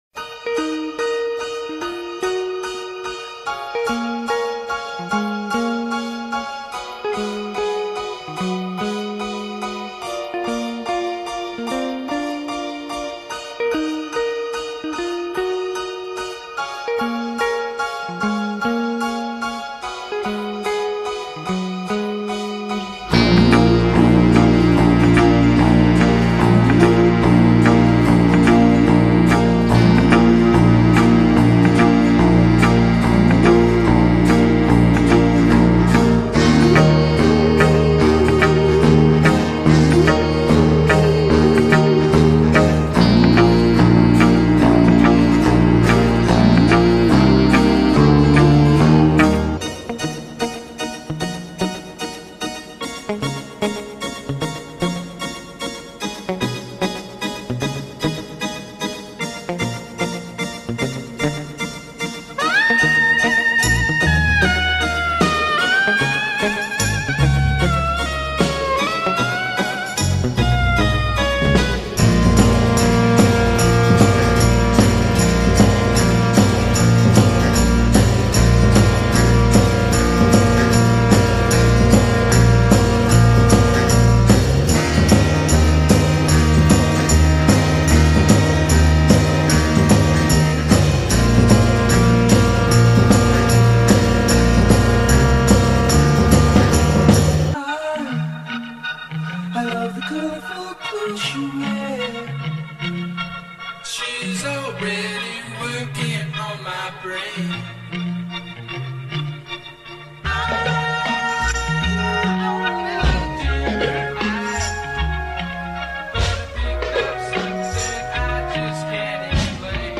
(Long version - 1966 mix)
... including the early lyrics
Pure CELESTIAL SYMPHONY 😇